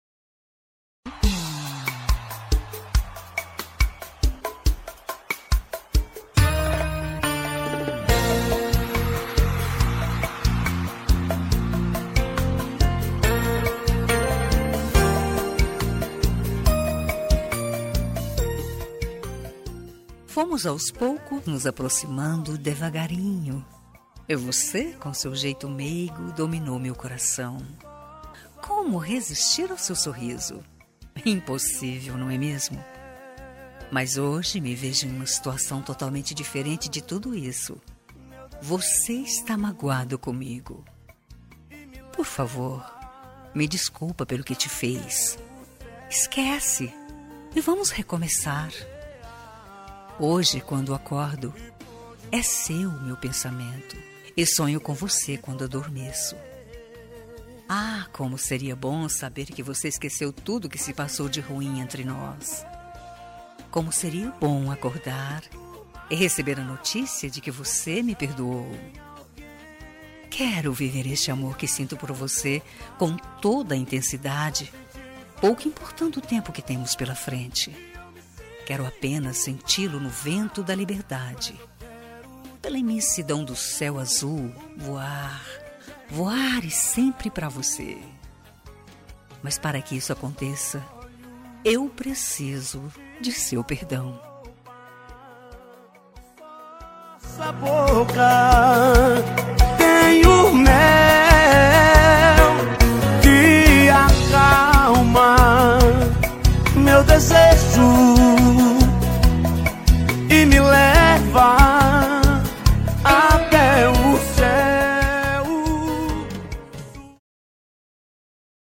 Telemensagem de Reconciliação – Voz Feminina – Cód: 8001 – Linda
8001-recon-fem-rom.m4a